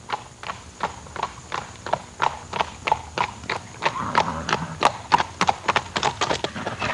Galloping Horse Sound Effect
Download a high-quality galloping horse sound effect.
galloping-horse.mp3